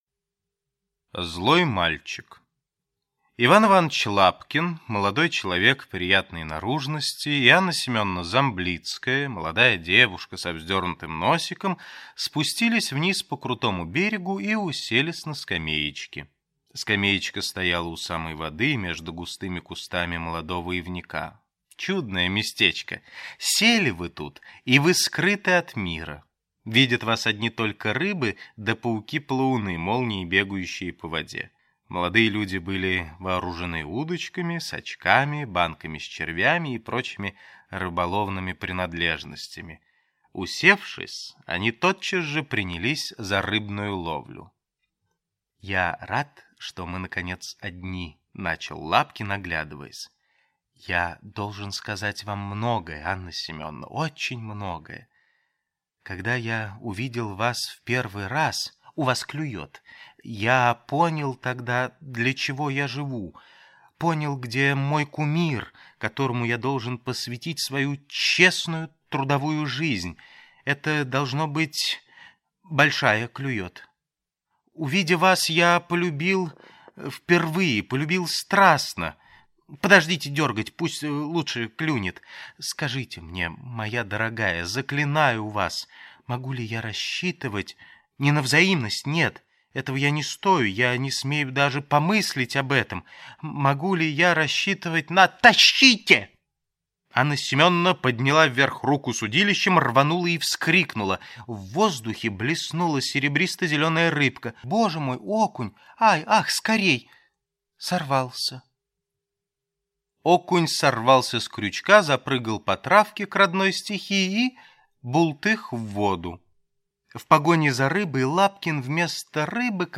Аудиокнига Чехов детям. «Ванька» | Библиотека аудиокниг